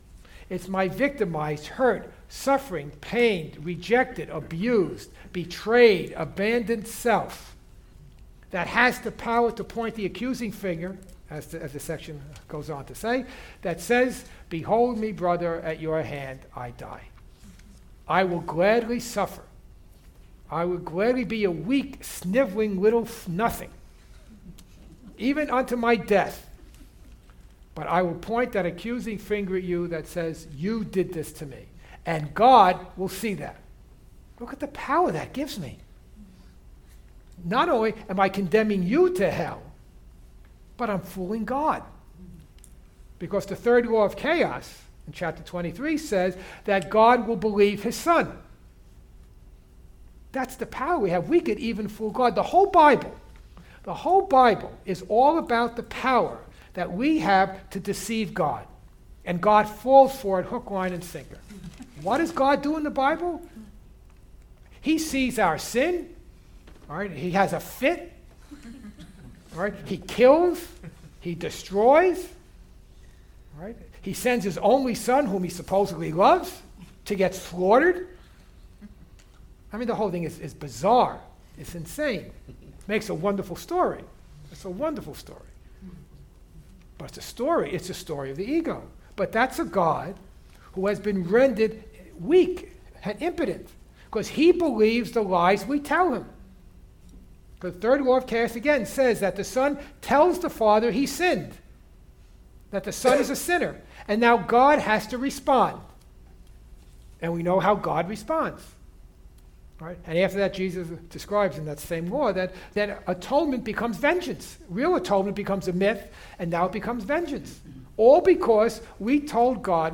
The title of this workshop, “The Tyranny of Needs,” encapsulates the ego thought system.